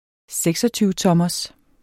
Udtale [ ˈsεgsʌtyːvəˌtʌmʌs ]